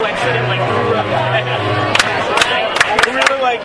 描述：燃烧的火焰的声音设计。基于对样本fireMX4.aif所用的相同录音材料的处理。一些声音材料用一套由LFO控制的带通滤波器处理，然后用过滤的白噪声和一些混乱的噪声函数调制。为了模拟火焰燃烧的声音特征，我把几个失真和 "去噪 "效果（比特深度调制、采样率降低等）连在一起。
标签： 比赛中 现场记录 防火 隔音 处理 声音设计
声道立体声